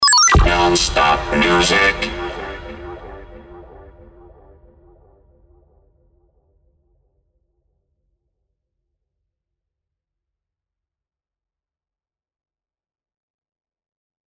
Met Soundeffects